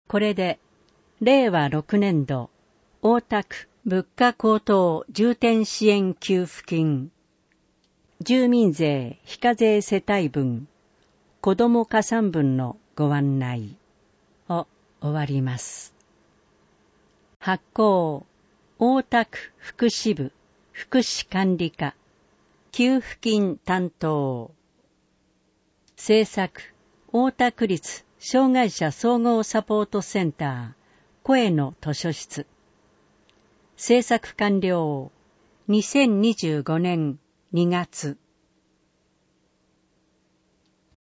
音声版
なお、この音声版は、障がい者総合サポートセンター声の図書室で製作したCDを再生したものです。